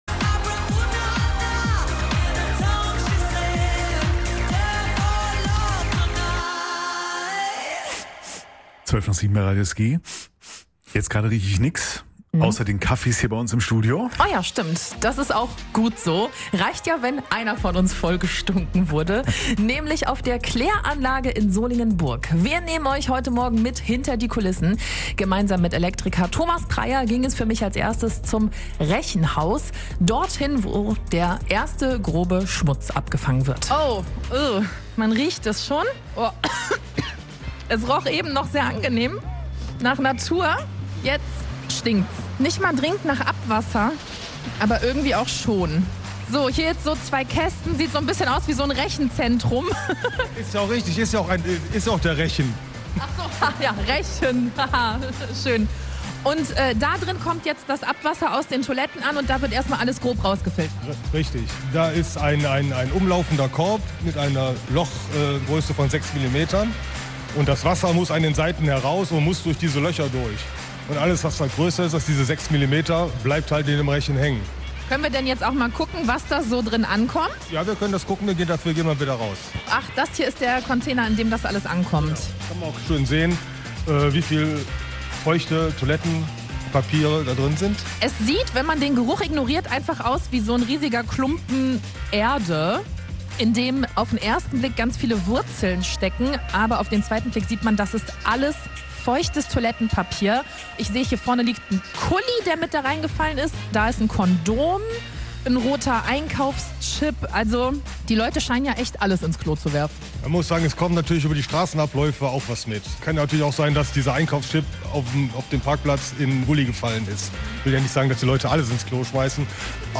Reportage aus dem Klärwerk Solingen Burg - Teil 2